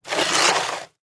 Shoveling snow on a concrete sidewalk. Scrape, Shovel